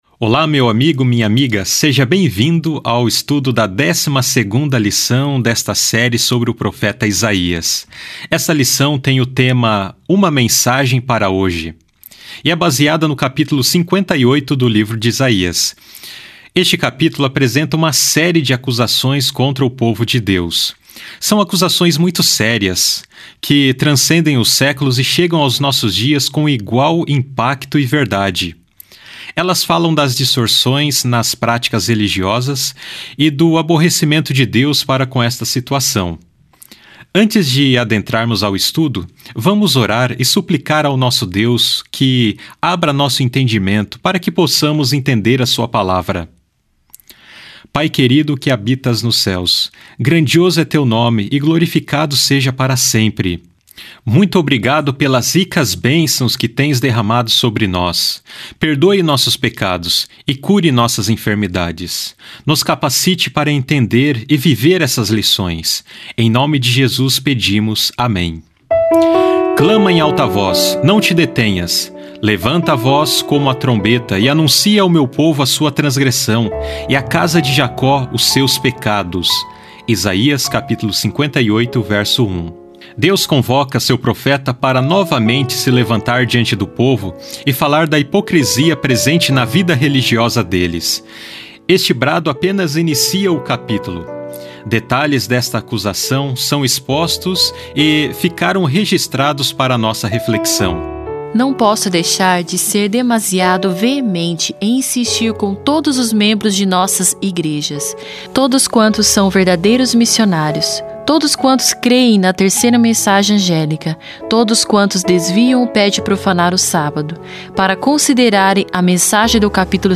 Áudios - Lição em Áudio